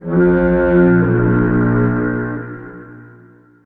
Added a foghorn sound effect for the second round.